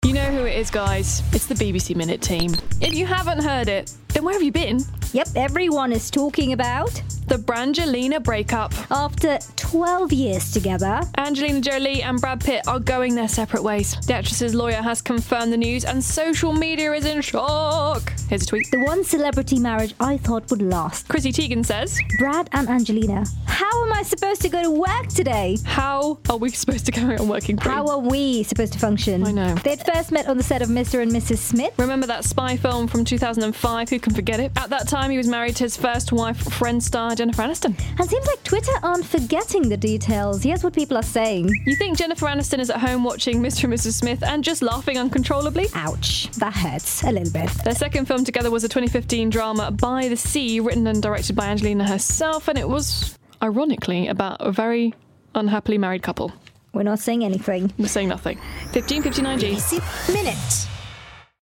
Breaking news of Brad Pitt and Angelina Jolie divorce on BBC Minute.